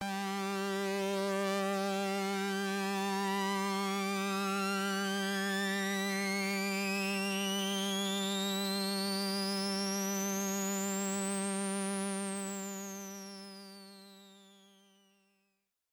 标签： FSharp4 MIDI音符-67 赤-AX80 合成器 单票据 多重采样
声道立体声